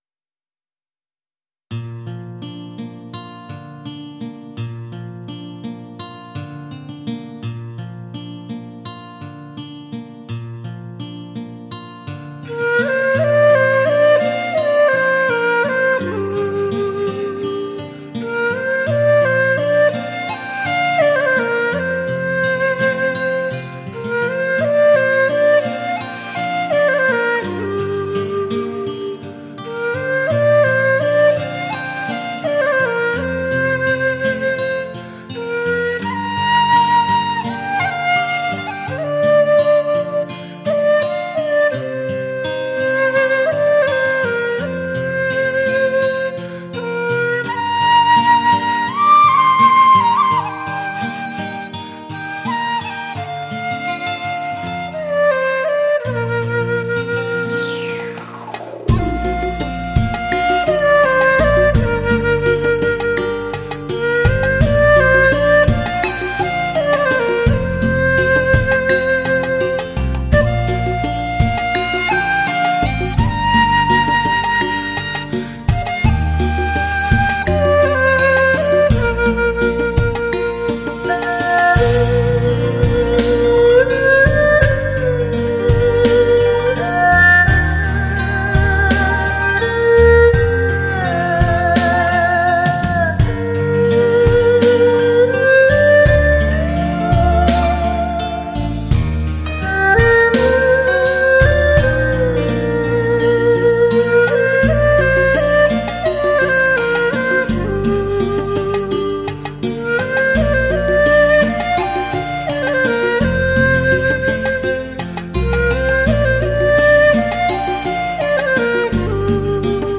二胡
古筝
琵琶
笛箫埙
吉它
小提琴
中国特色的古典与流行“跨界示范”之作